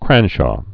(krănshô)